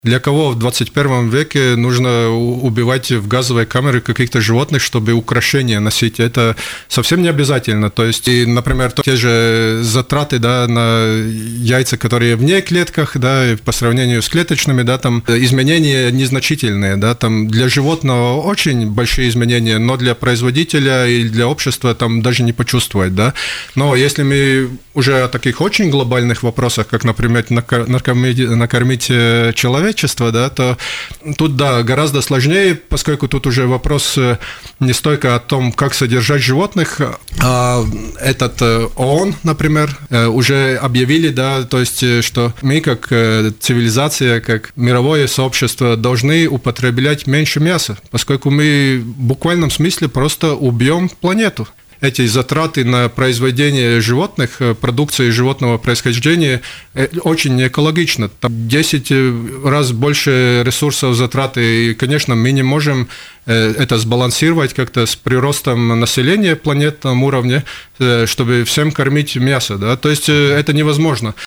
Человечество должно постепенно снижать уровень потребления мяса, заявил в эфире радио Baltkom